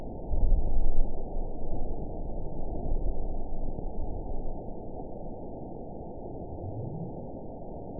event 920098 date 02/22/24 time 19:36:19 GMT (1 year, 2 months ago) score 9.49 location TSS-AB01 detected by nrw target species NRW annotations +NRW Spectrogram: Frequency (kHz) vs. Time (s) audio not available .wav